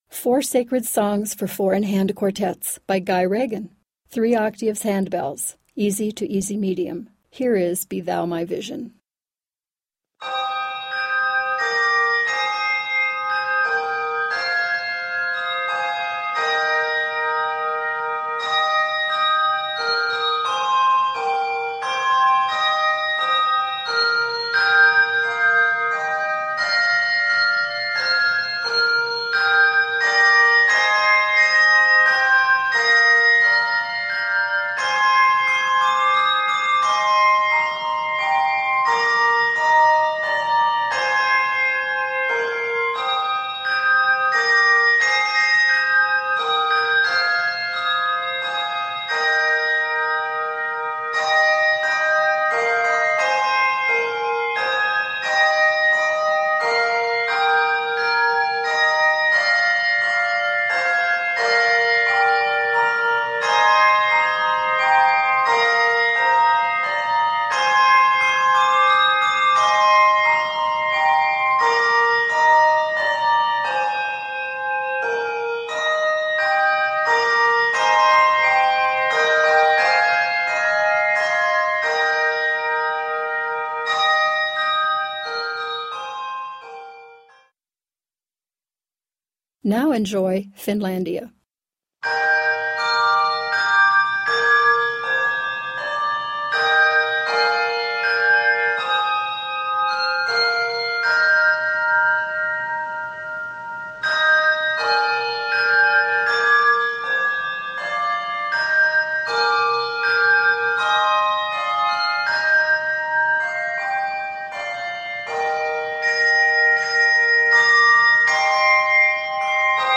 4-in-hand ensemble
Hymn Tune